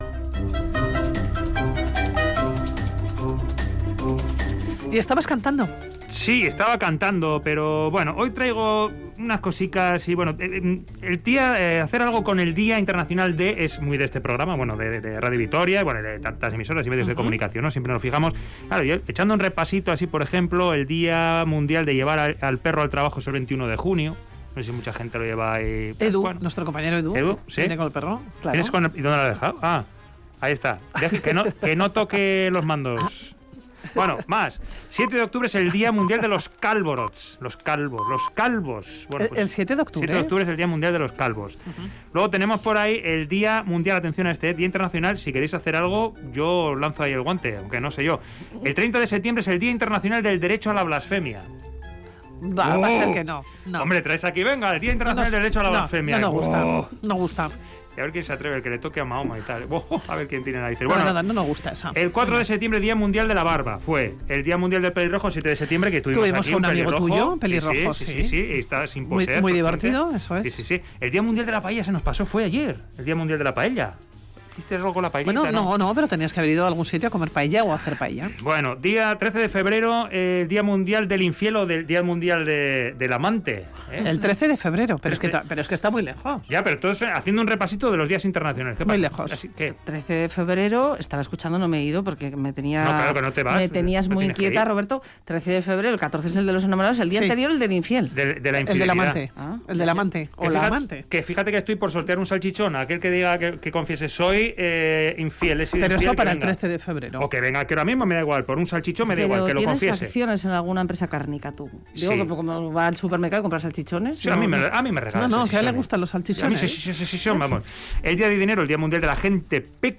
Audio: Reportaje: Aprenda a hablar como un pirata